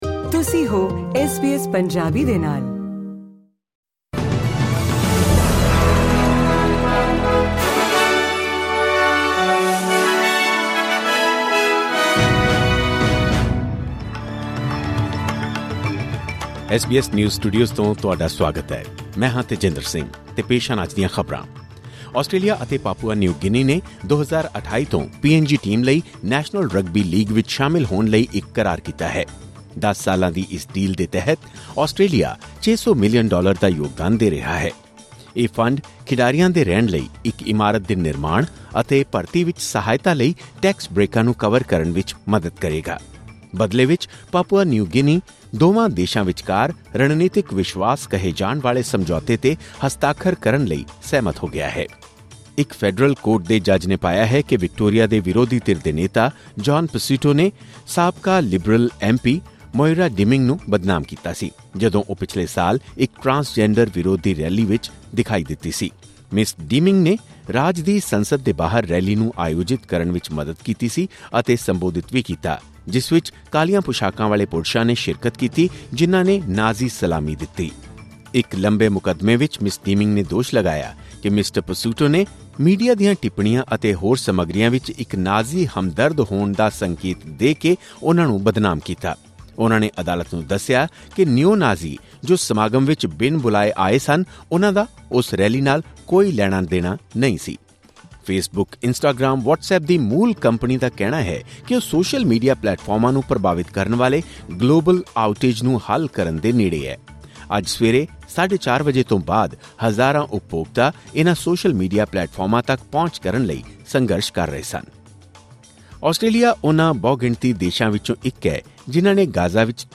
ਖਬਰਨਾਮਾ: ਨੈਸ਼ਨਲ ਰਗਬੀ ਲੀਗ ਵਿੱਚ ਸ਼ਾਮਲ ਹੋਣ ਲਈ ਪਾਪੂਆ ਨਿਊ ਗਿਨੀ ਟੀਮ ਲਈ ਕਰਾਰ